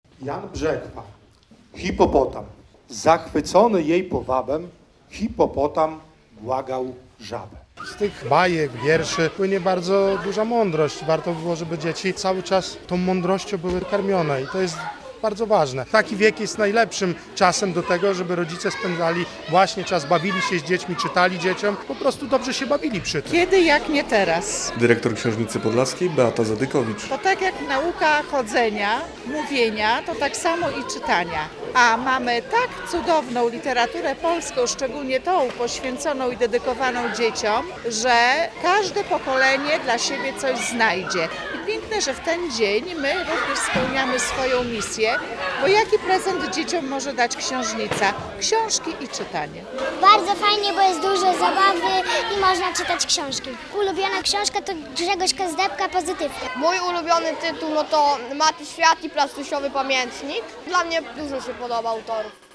W akcji wzięły udział przedszkolaki i uczniowie szkoły podstawowej z Zabłudowa, a wiersze czytał marszałek województwa podlaskiego Artur Kosicki i nowa maskotka czytelni Łoś Czytuś.